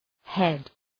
head Προφορά
{hed}